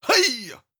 26. Effort Grunt (Male).wav